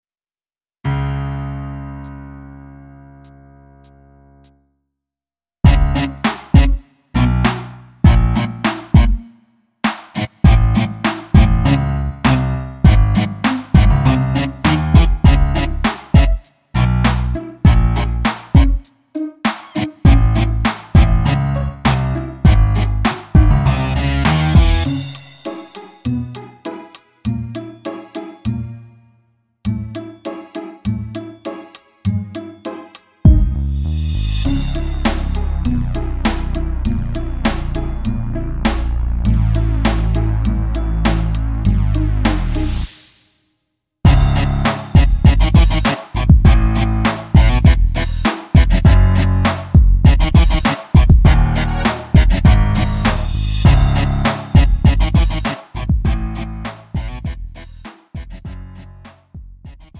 축가 및 결혼식에 최적화된 고품질 MR을 제공합니다!
음정 -1키
장르 가요